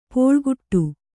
♪ pōḷkuṭṭu